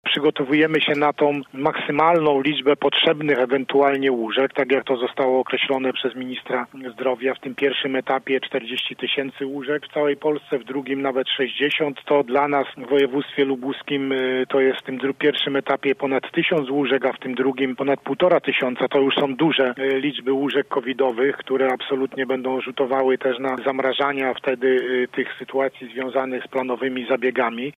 – Do wzrostu liczby zakażeń, a co za tym idzie hospitalizacji z powodu Covid-19, przygotowuje się także nasz region – mówił dziś w „Rozmowie po 9”, Władysław Dajczak, wojewoda lubuski: